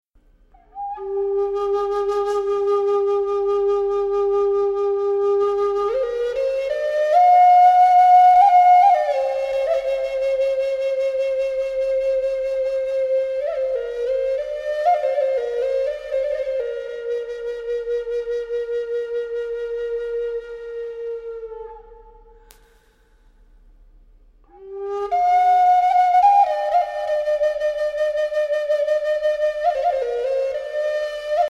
Ideal for relaxation and/or meditation